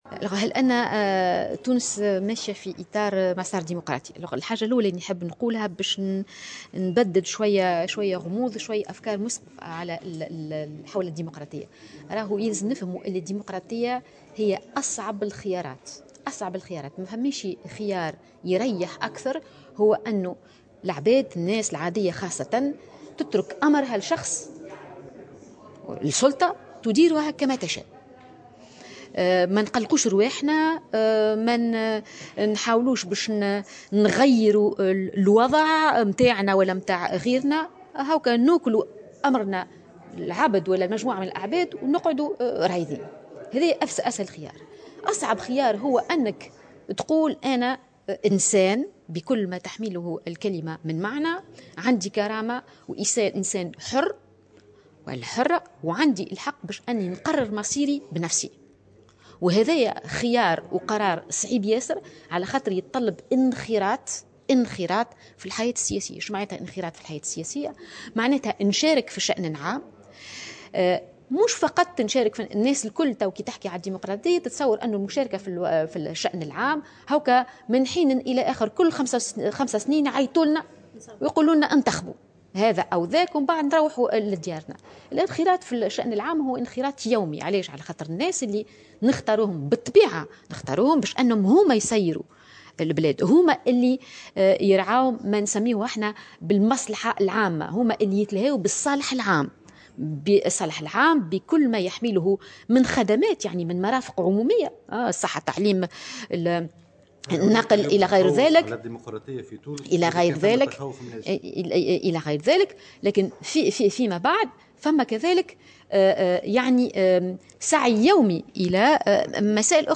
في تصريح لمراسلة الجوهرة اف ام
على هامش ندوة علمية دولية حول الديمقراطية